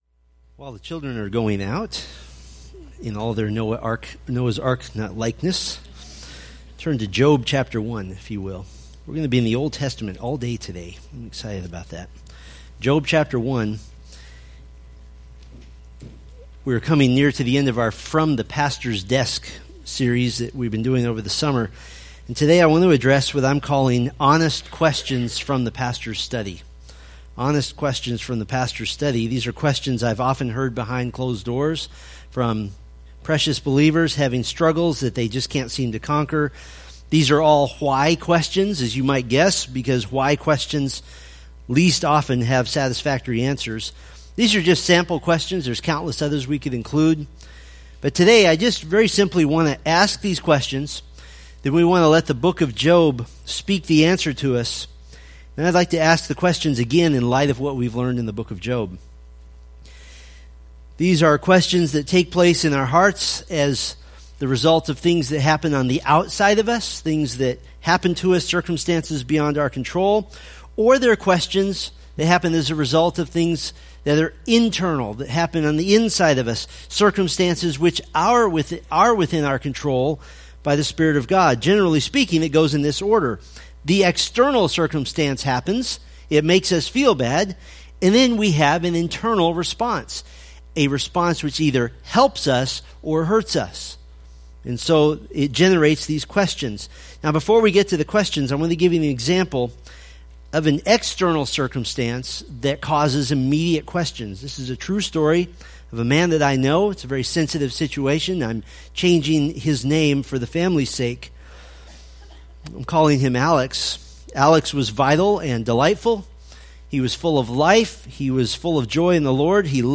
Preached September 4, 2016 from Book of Job